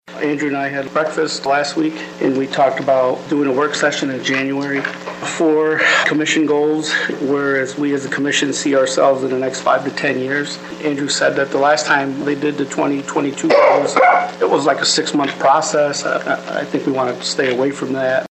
During Monday’s Sturgis City Commission meeting, Perez shared that he and City Manager Andrew Kuk met recently to discuss setting goals for the Commission.